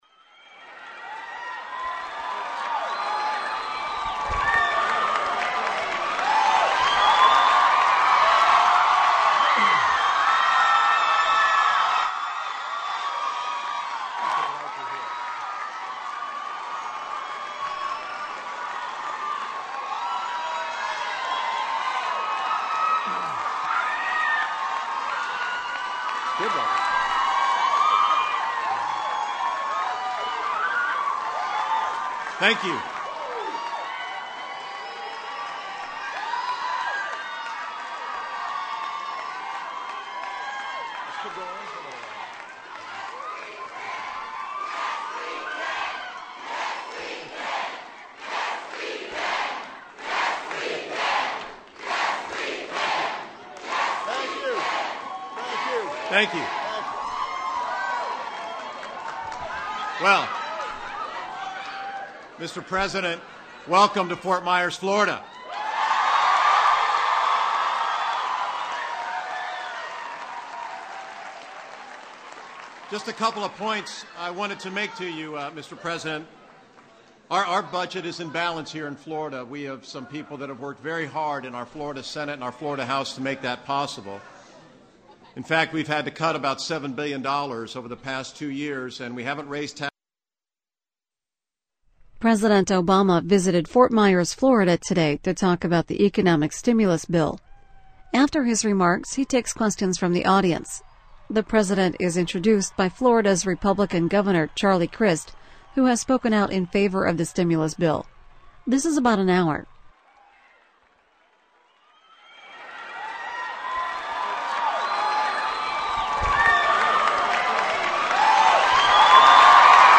U.S. President Barack Obama holds a town hall meeting in Ft. Myers, FL, to discuss the economic recovery plan